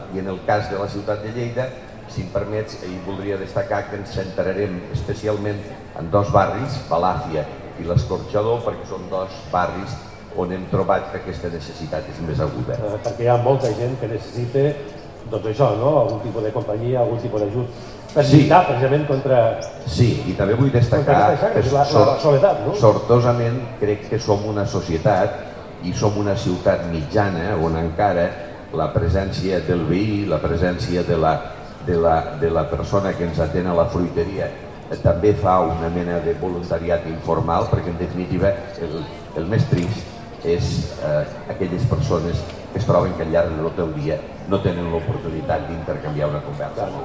tall-de-veu-de-lalcalde-miquel-pueyo-sobre-el-programa-sempre-acompanyats-i-la-marato-contra-la-soledat